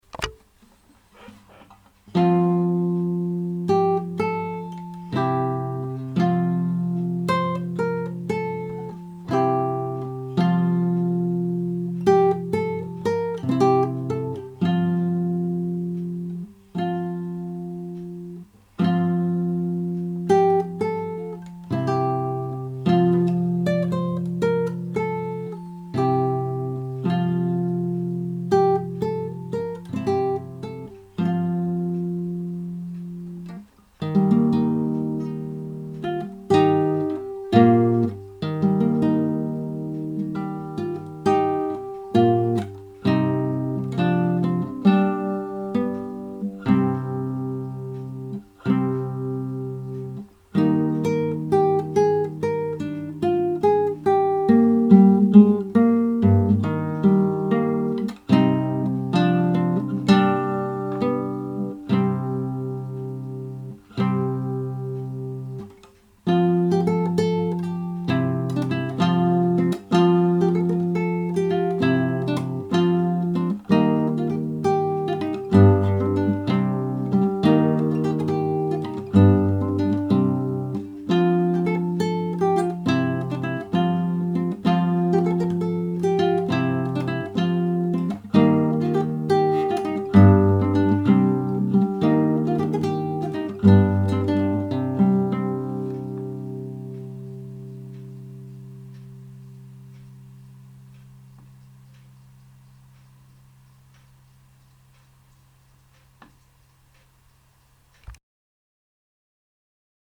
My focus is on music by John Dowland from the late 1500s/early 1600s, originally composed for the lute and carefully adapted for classical guitar.
Renaissance Period